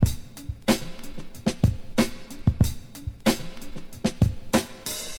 break.wav